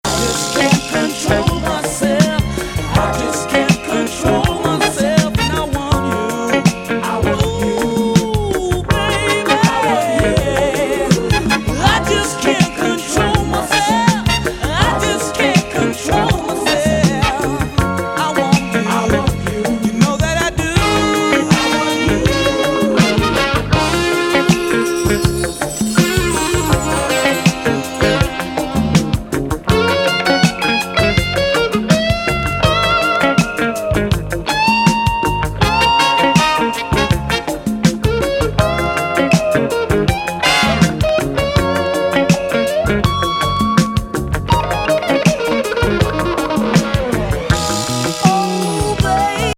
デトロイトの10人組ファンキー・ディスコ・バンド、79年作。